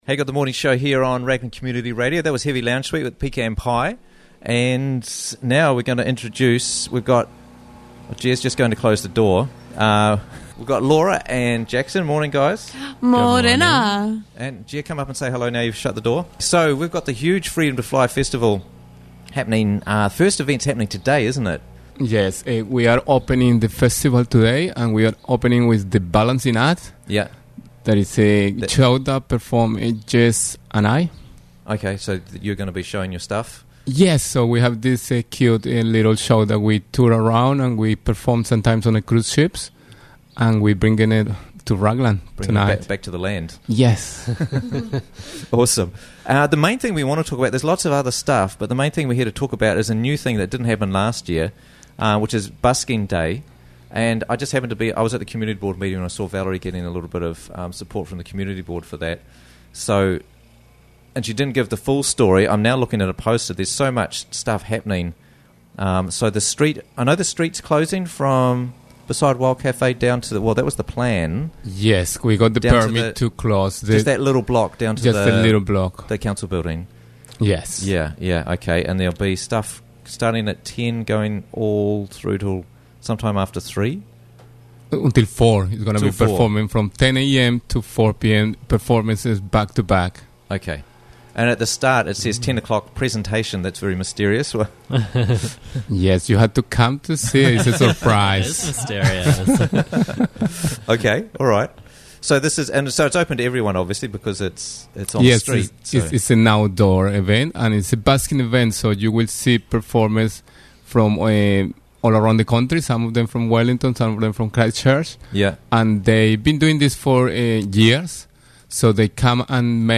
Interviews from the Raglan Morning Show